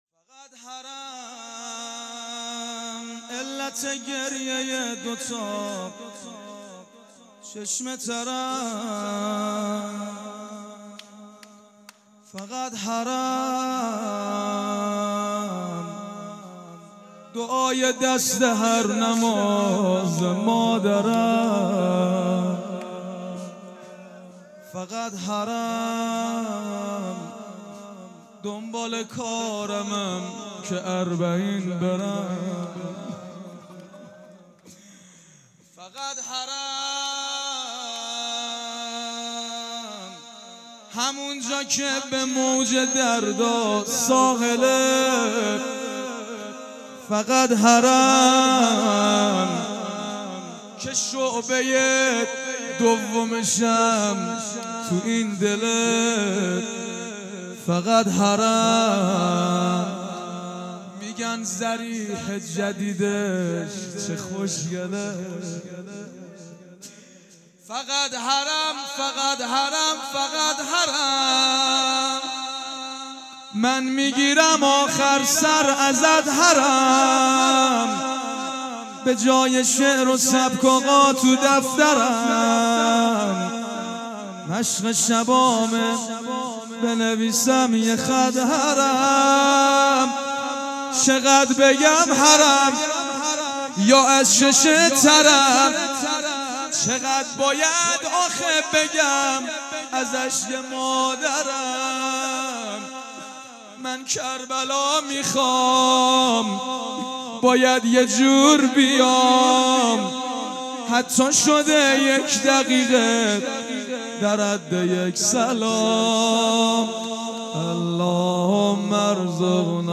مناجات
فقط حرم|شهادت حضرت رقیه ۱۵ آبان ۹۵